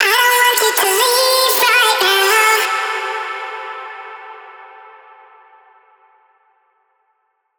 VR_vox_hit_dontgo2_E.wav